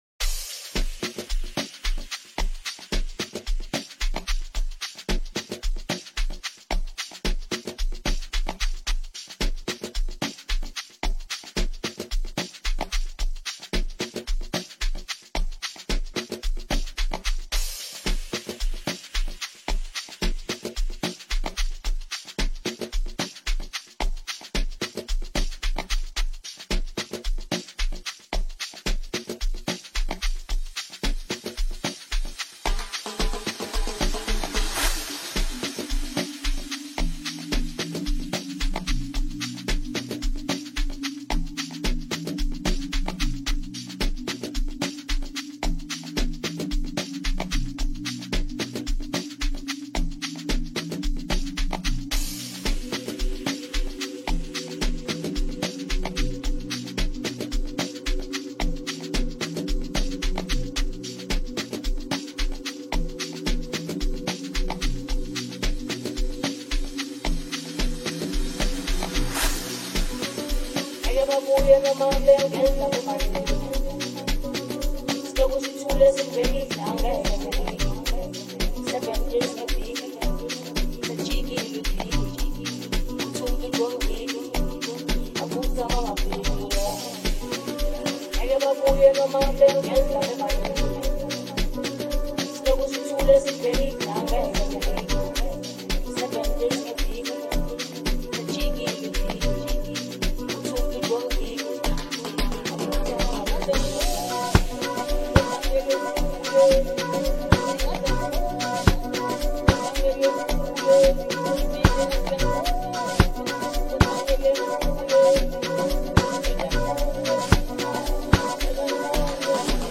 compelling rushing basslines
scattered vocals
The drop is bouncy and perfect for recapping festive vibes.